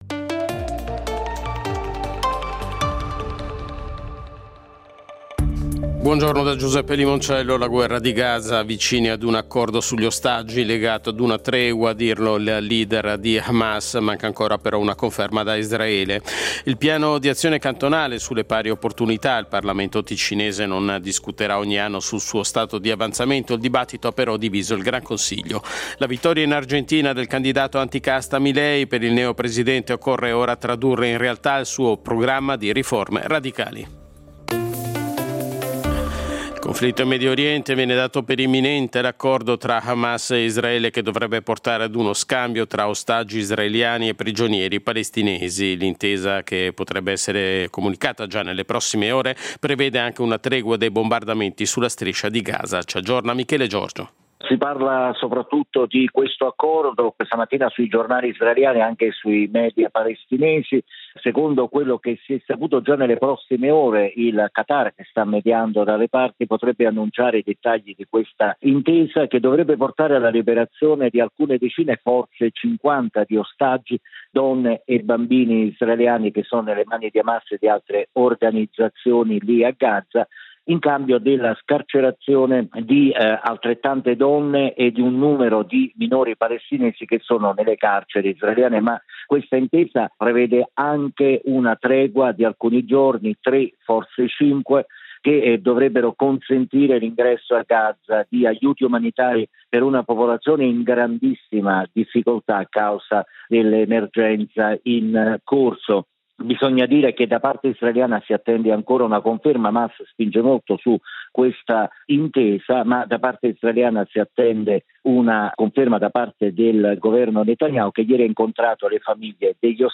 Notiziario delle 08:00 del 21.11.2023